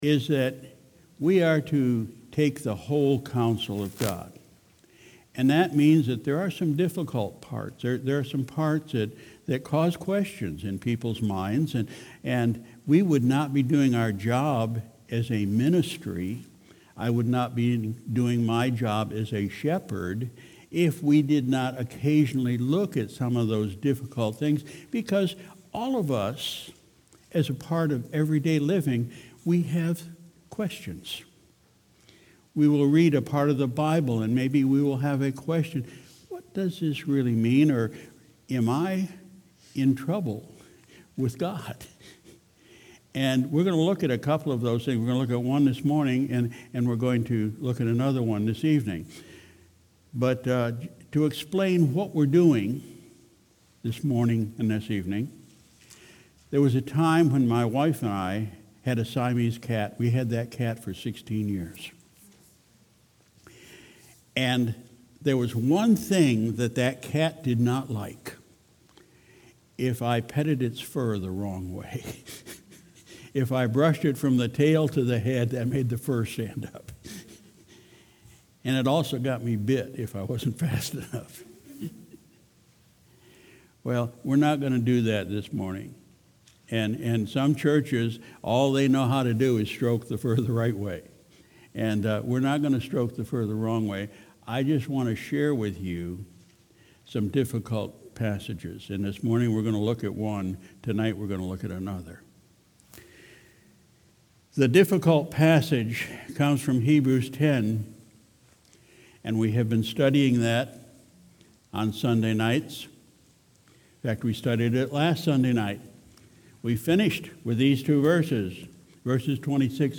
Sunday, June 23, 2019 – Morning Service